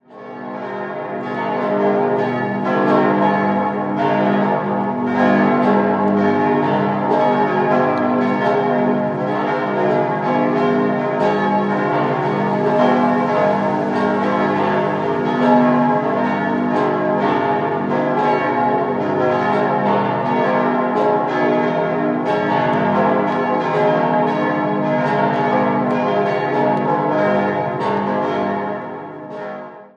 6-stimmiges ausgefülltes As-Dur-Geläute: as°-b°-c'-es'-f'-as' Die Glocke 2 wurden im Jahr 1900 von der Firma Gugg gegossen, die anderen entstanden 1948 in der Gießerei Otto in Bremen-Hemelingen.